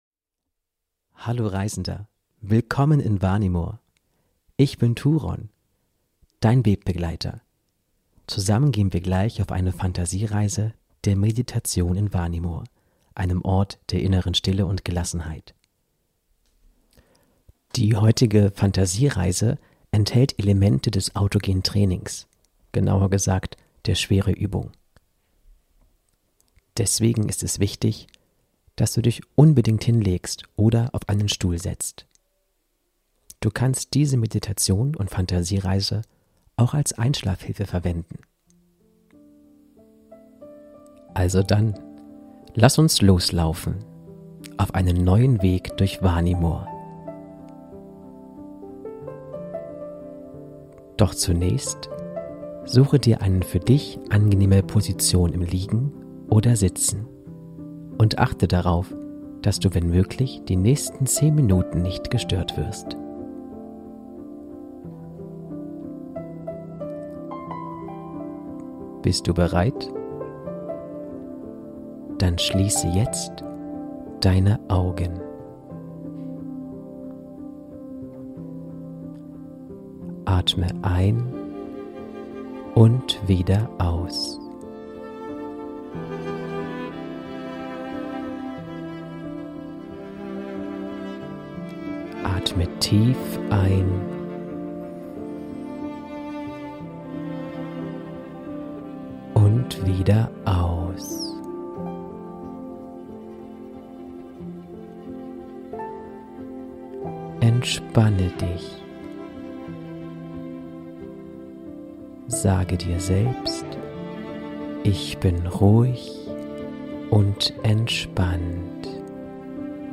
Diese Meditation enthält Elemente des autogenen Trainings.